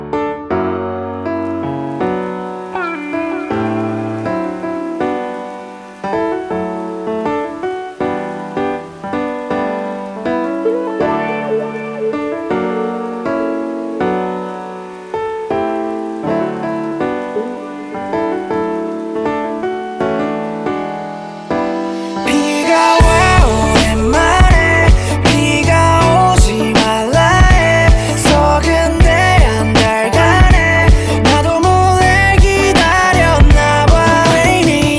# Korean Indie